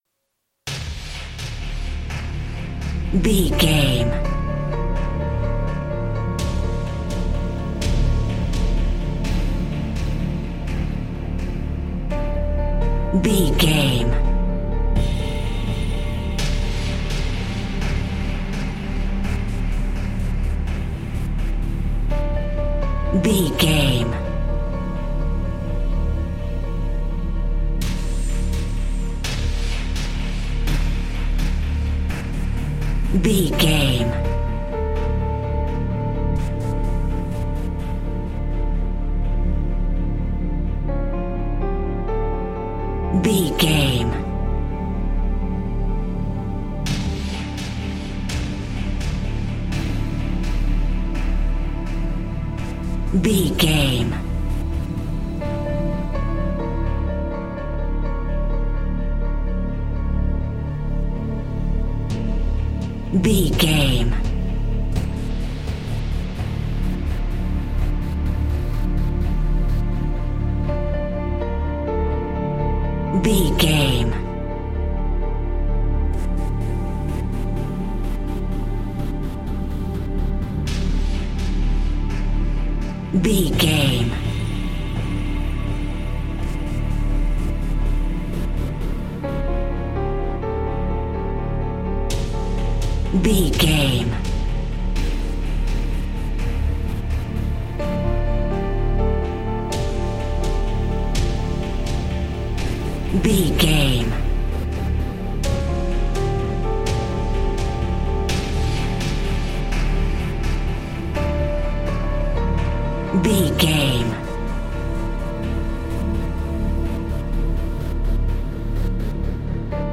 Thriller
Ionian/Major
D
percussion
synthesiser
piano